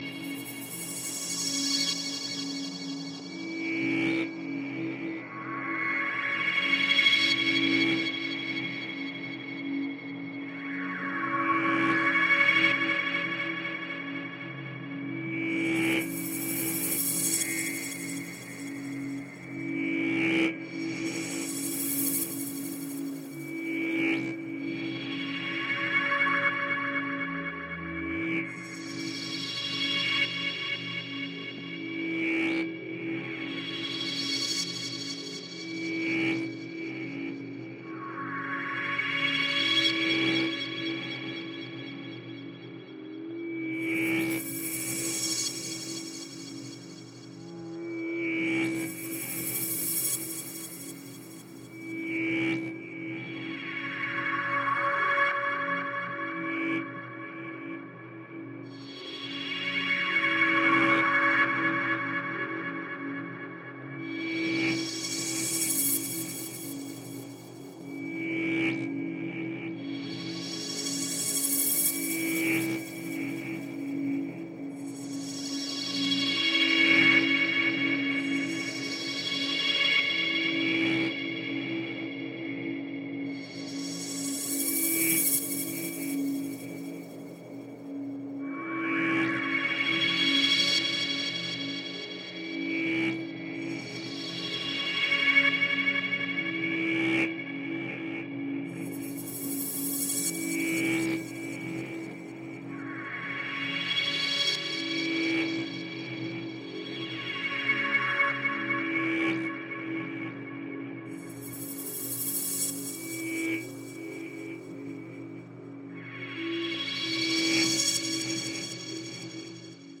Звуки фантастики
Звук покорения межзвездных просторов